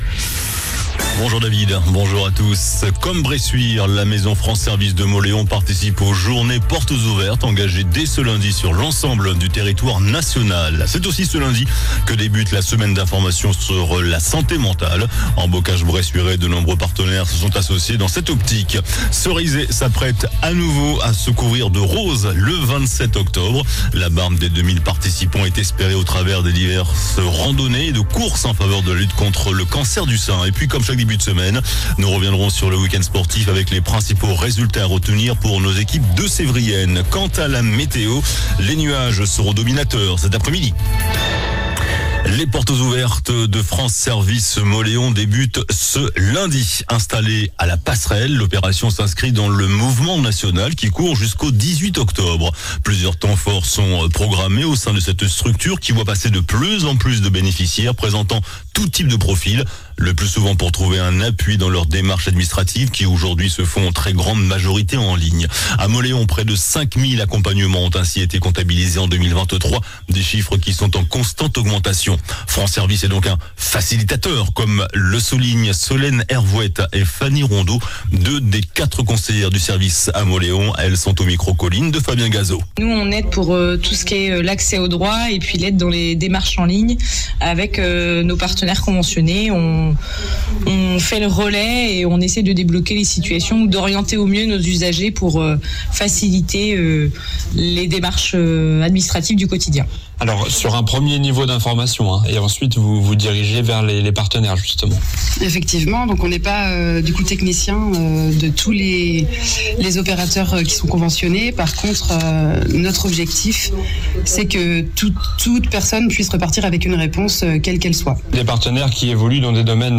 JOURNAL DU LUNDI 07 OCTOBRE ( MIDI )